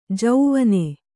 ♪ jauvane